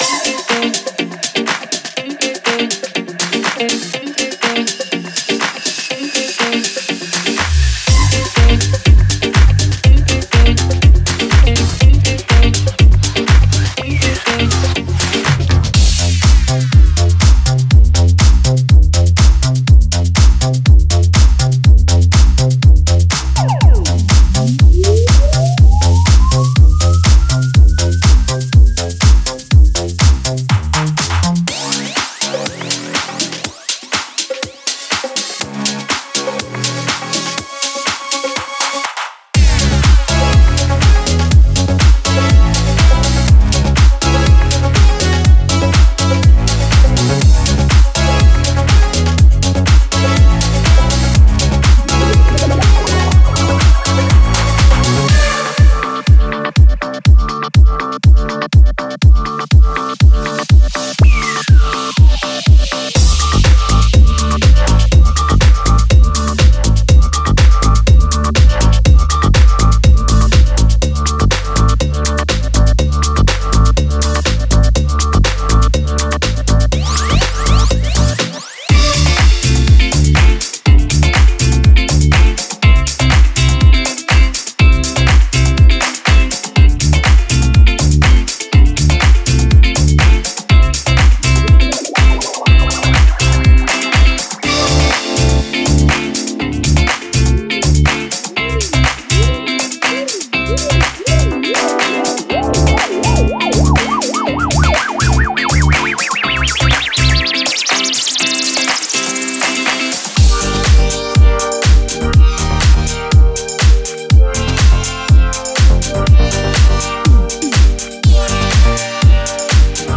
Recordings in London, Warsaw and Amsterdam.